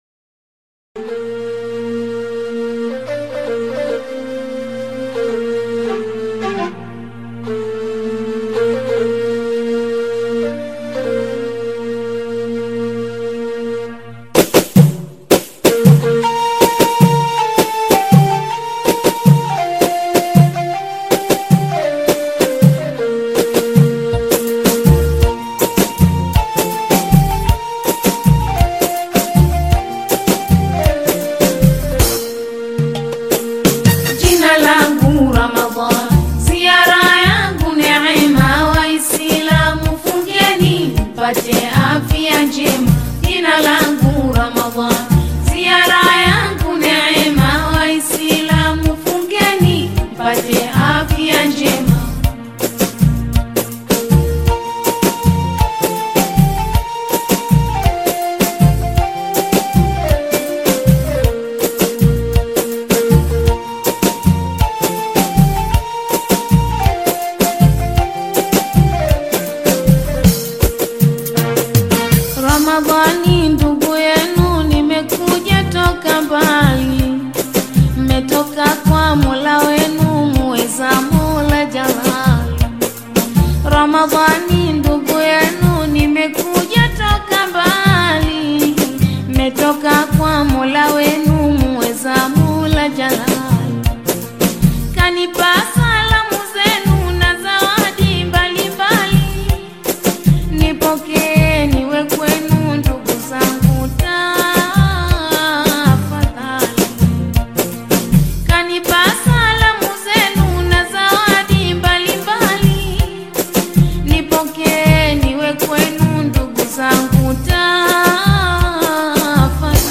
AudioQaswida
Qaswida/Islamic devotional song